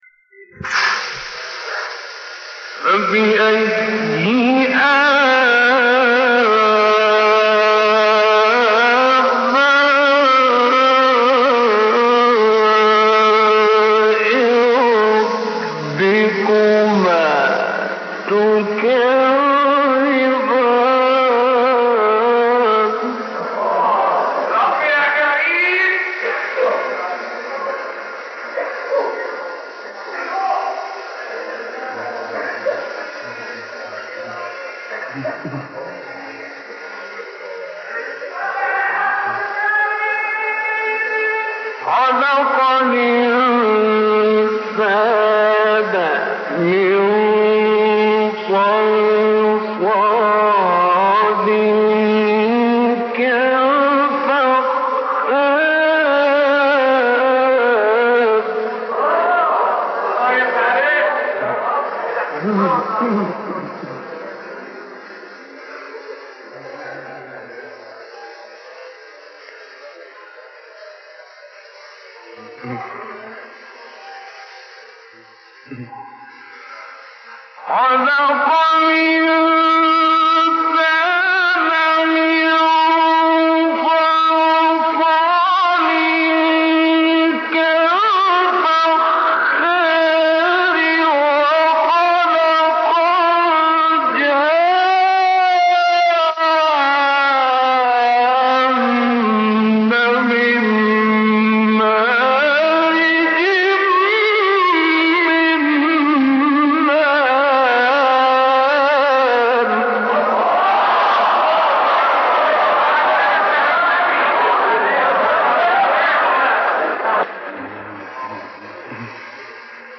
مقام الراست ( تلاوة ) ـ الشيخ غلوش ـ الرحمن - لحفظ الملف في مجلد خاص اضغط بالزر الأيمن هنا ثم اختر (حفظ الهدف باسم - Save Target As) واختر المكان المناسب